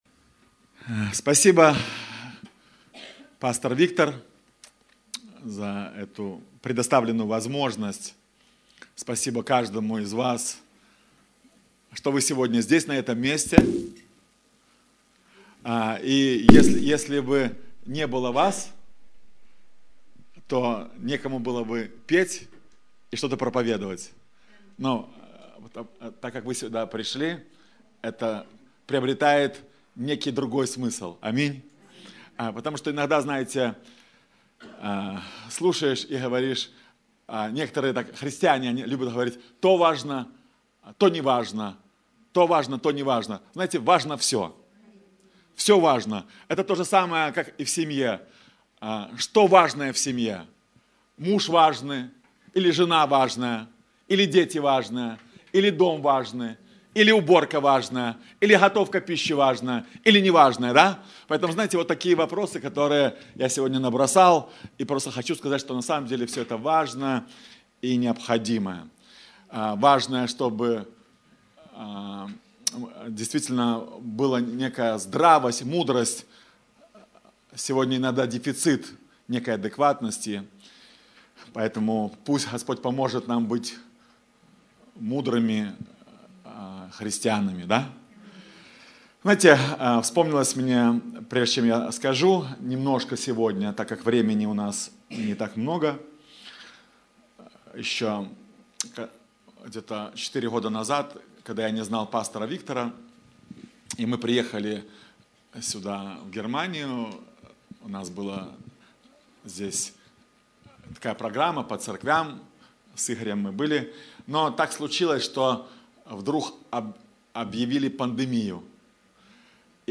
Церковь Живого Бога «Путь Христа»